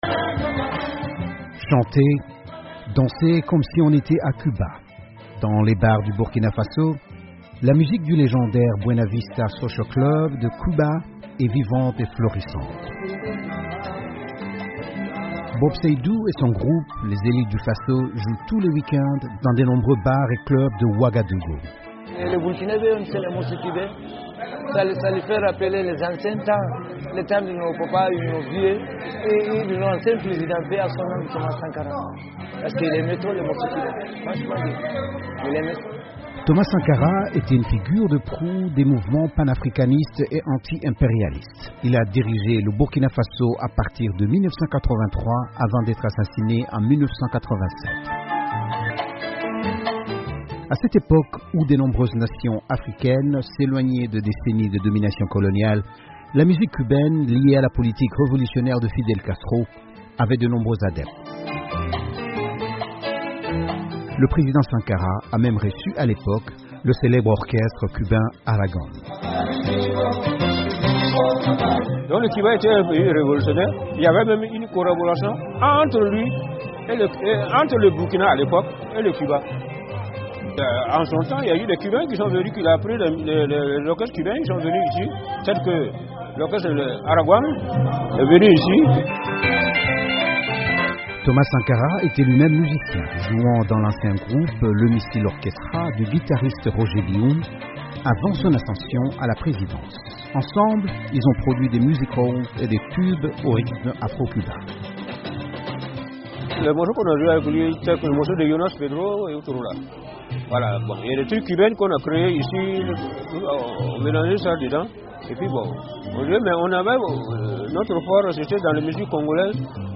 De nombreux bars de Ouagadougou réservent des groupes de style cubain
Au Burkina Faso, de nombreux bars de la capitale réservent des groupes de style cubain pour jouer les tubes du légendaire Buena Vista social club.